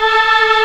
Index of /90_sSampleCDs/Giga Samples Collection/Organ/MightyWurltzBras